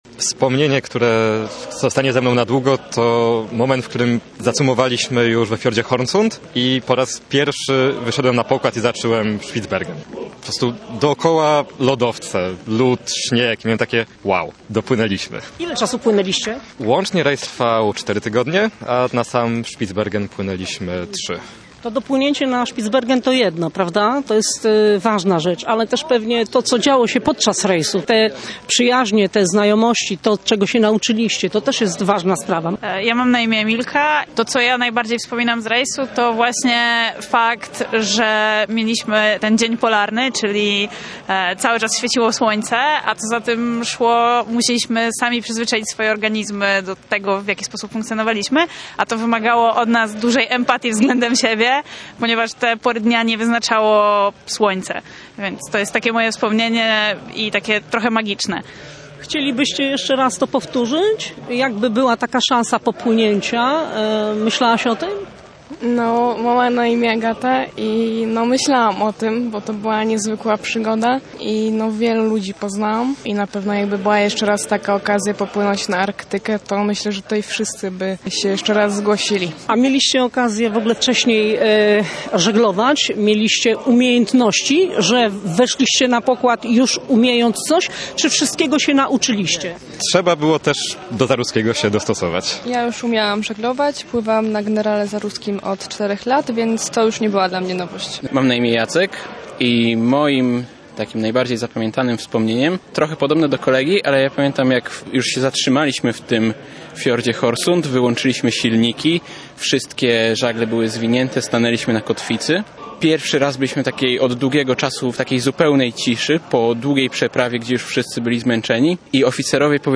Uroczyste powitanie odbyło się w strugach deszczu, ale w gorącej atmosferze. Oczekujących na nabrzeżu rozgrzewał Męski Chór Szantowy „Zawisza Czarny”.